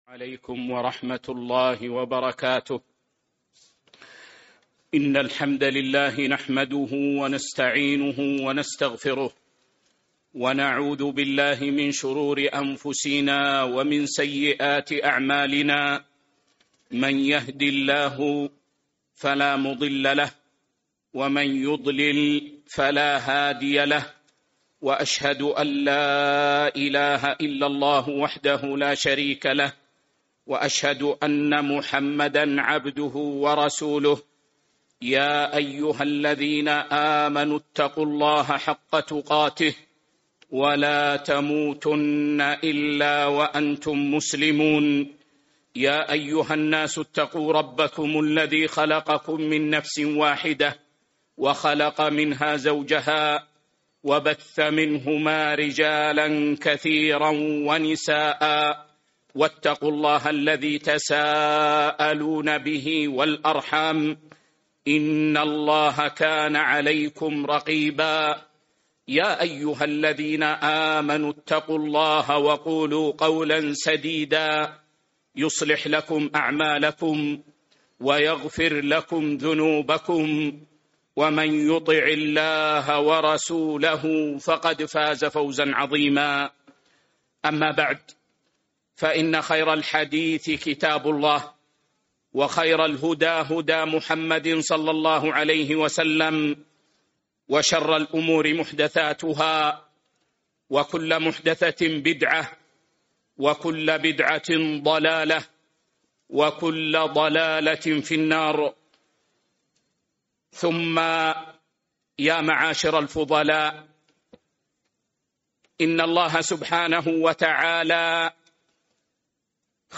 محاضرة: منهج السلف الصالح وعوامل تحقيقه وآثارها | بالمسجد النبوي ١٤٤٦/٦/٤ هـ